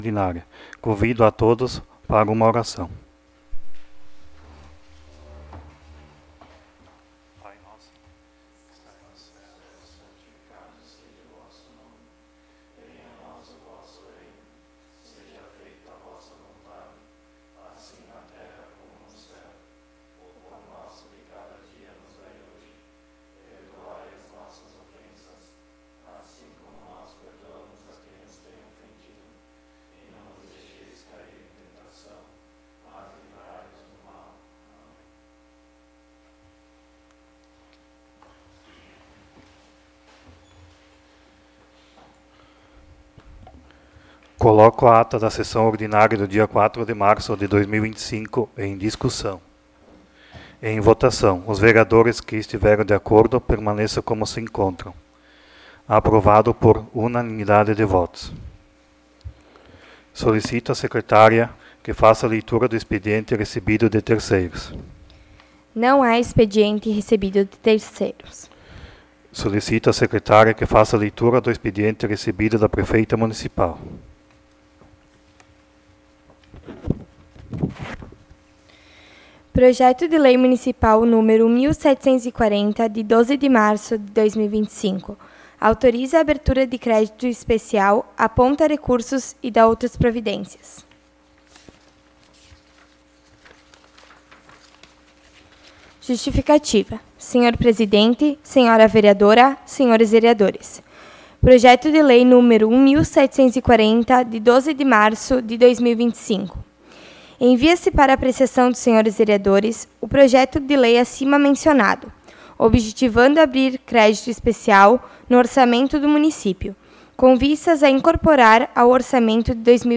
04° Sessão Ordinária de 2025